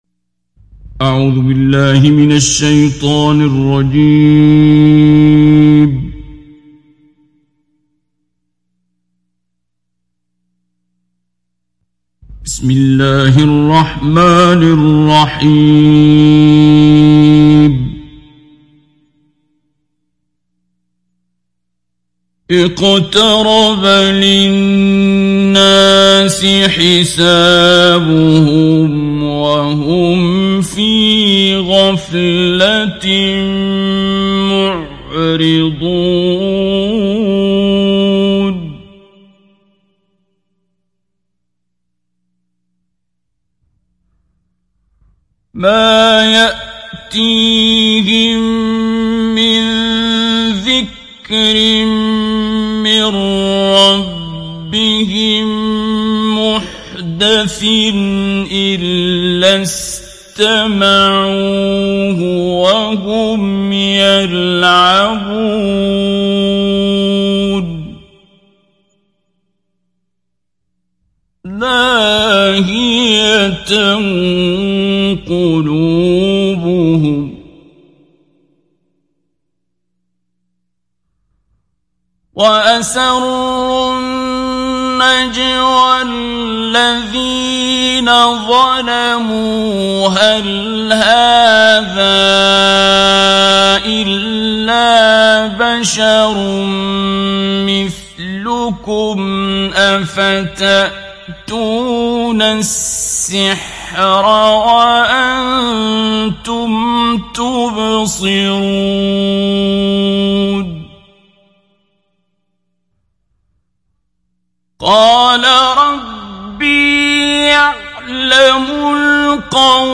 Download Surat Al Anbiya Abdul Basit Abd Alsamad Mujawwad